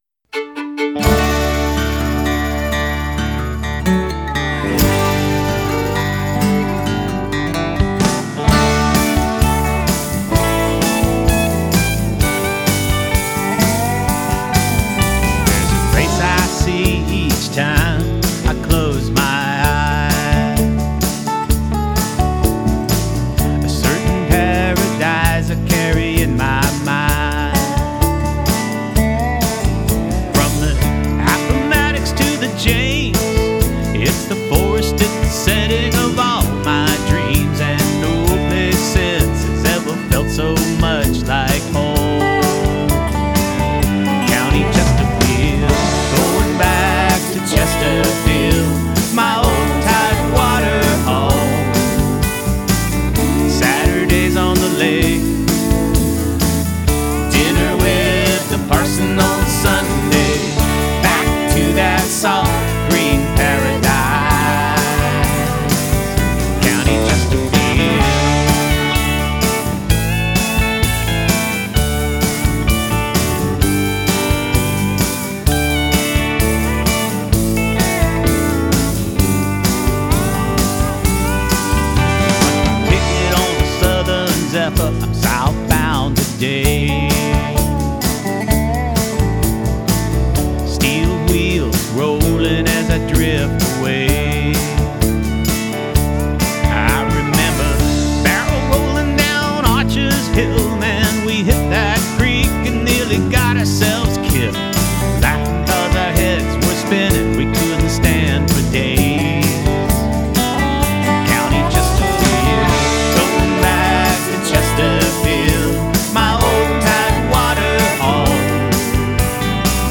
vocals, Telecaster guitar
MSA pedal steel, 67 Telecaster, dobro.
Pro Tools home recording, files sent remotely.